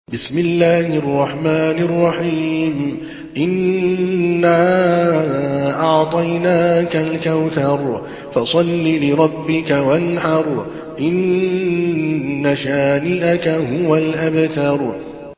Surah Al-Kawthar MP3 in the Voice of Adel Al Kalbani in Hafs Narration
Surah Al-Kawthar MP3 by Adel Al Kalbani in Hafs An Asim narration.
Murattal